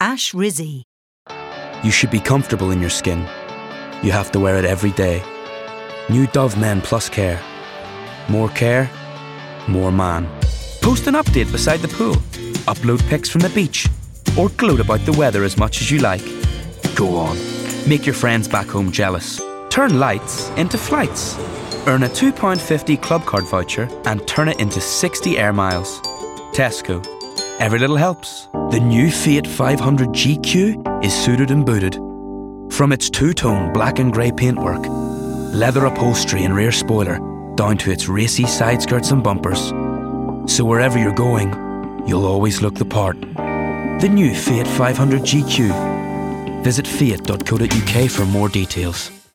Description: N Irish: versatile, warm, upbeat
Age range: 20s - 30s
Commercial 0:00 / 0:00